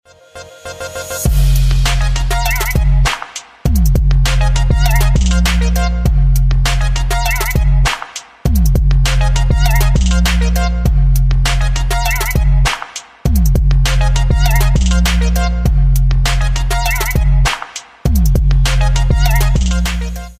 • Качество: 320, Stereo
Electronic
Trap